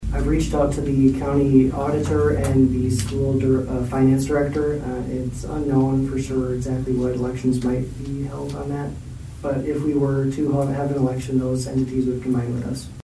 ABERDEEN, S.D.(HubCityRadio)- At Monday’s Aberdeen City Council meeting, the council released the official date for the upcoming elections for two city council seats.